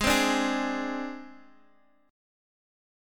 AbMb5 chord